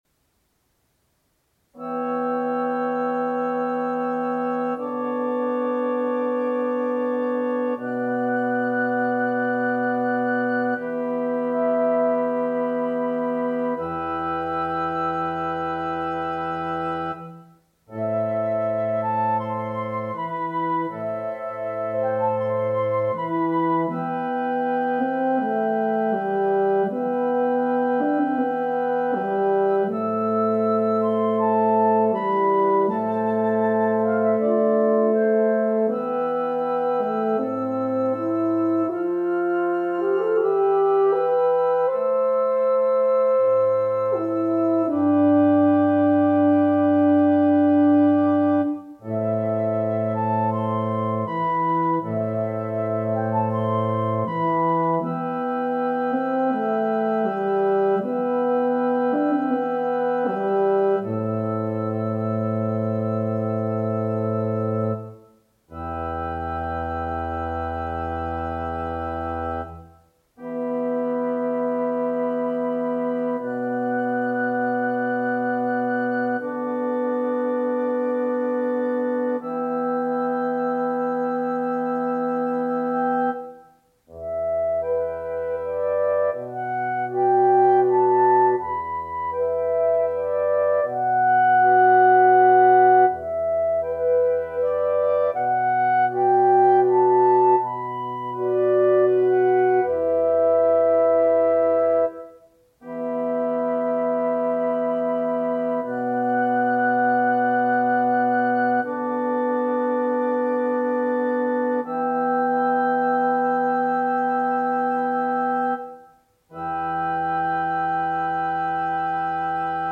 Wind Quintet
Instrumentation: Flute, Oboe, Clarinet, Horn in F, Bassoon